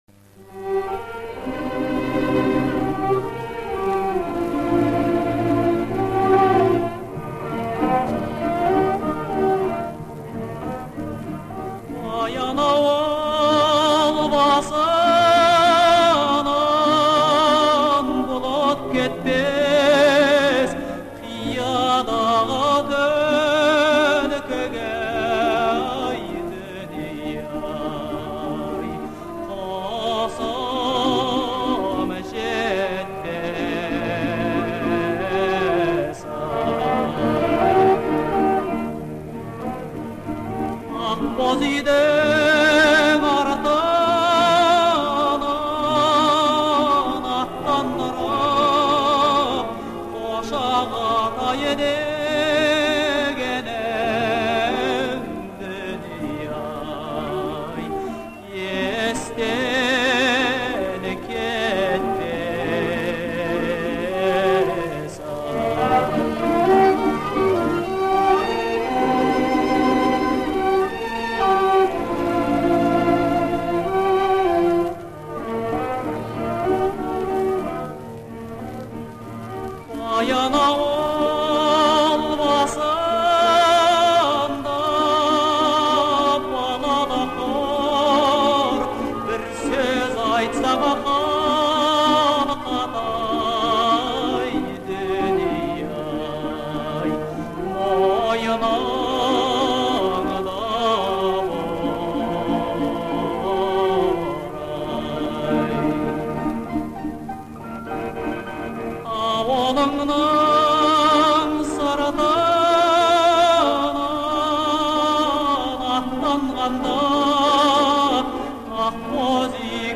исполненная в жанре казахского народного музыки.